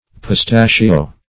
Pistachio \Pis*ta"chio\, n. [It. pistacchio (cf. Sp. pistacho,